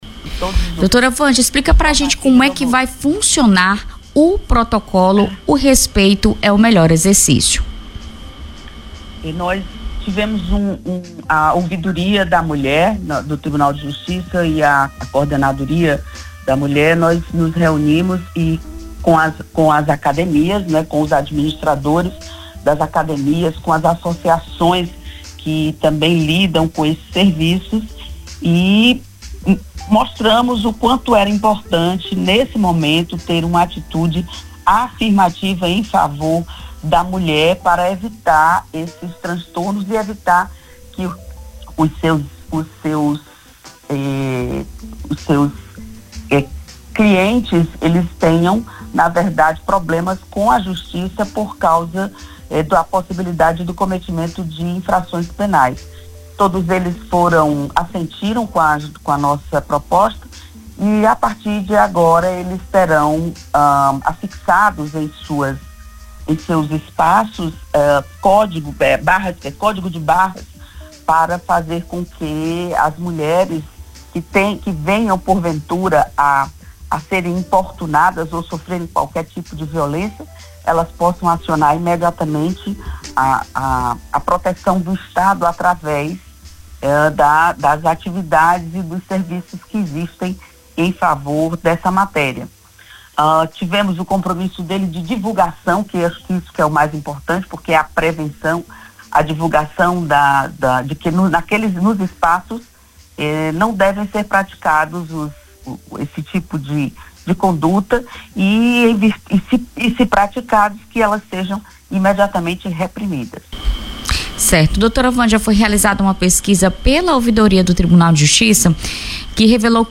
Entrevista do dia
Acompanhe, na íntegra, a entrevista com a Desembargadora Vanja Fontenele: